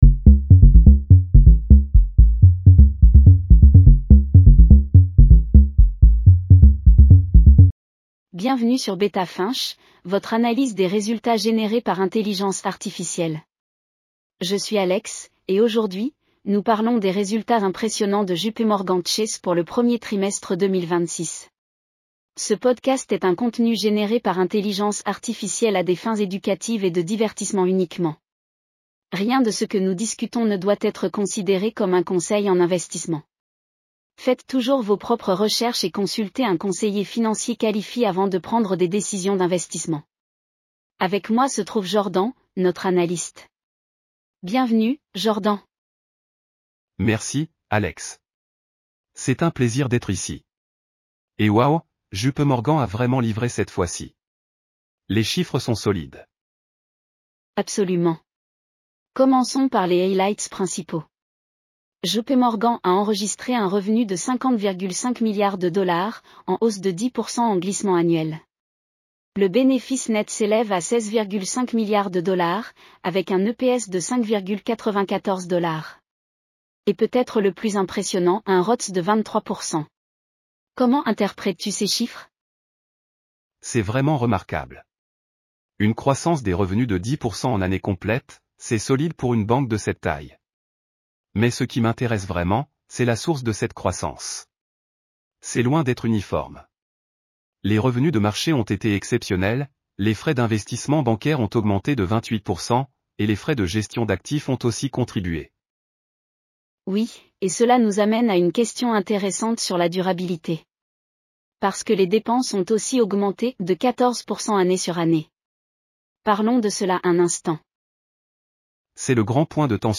JPMorgan Chase Q1 2026 earnings call breakdown.
Bienvenue sur Beta Finch, votre analyse des résultats générée par intelligence artificielle.